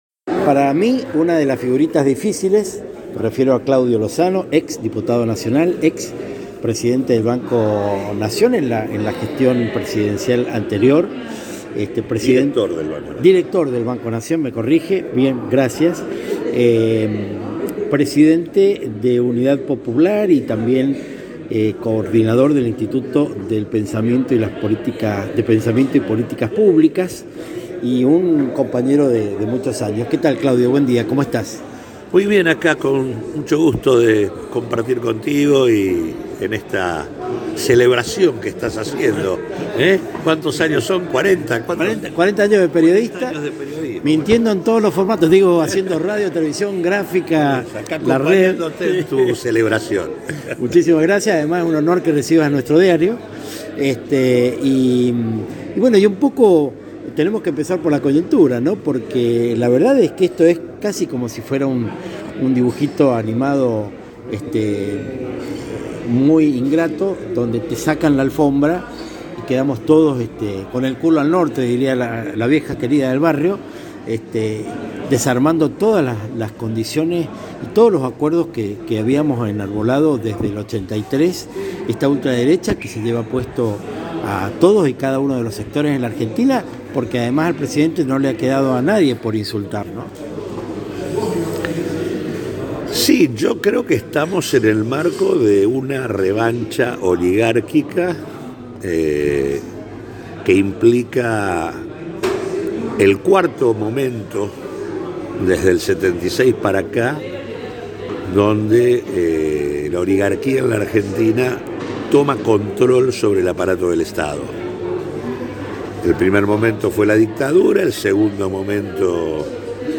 Por todo esto y mucho más es que me permito proponerte que escuches la entrevista que le hice en el bar La Puerto Rico, de la siempre bulliciosa Buenos Aires, que podrás encontrar a tris de un clic, líneas abajo.